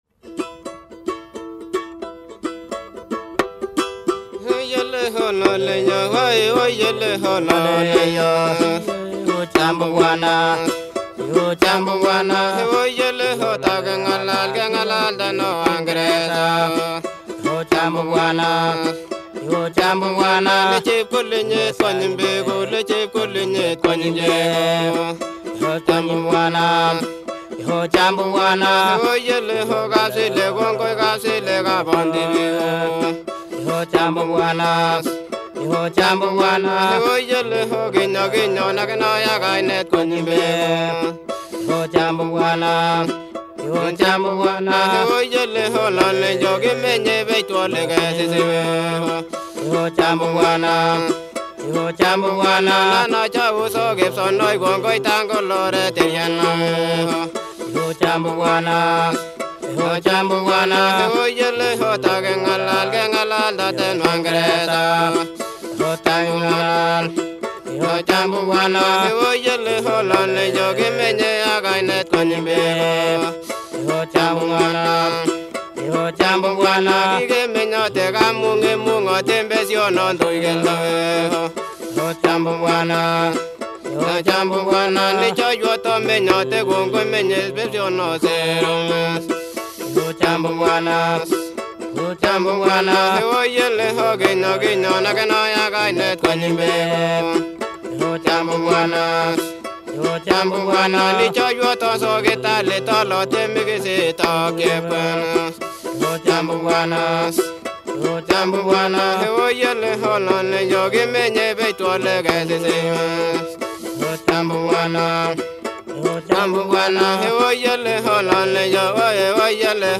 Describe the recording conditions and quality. Field recordings